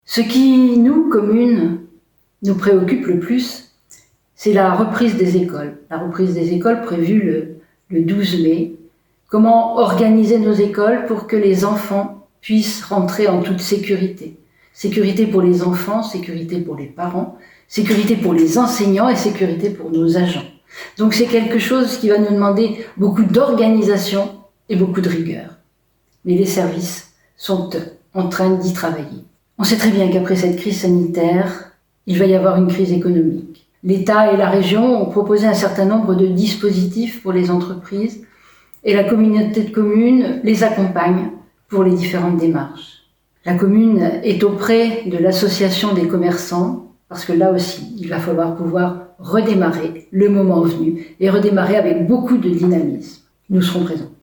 Déconfinement : la maire de Surgères parle aux Surgériens – HELENE FM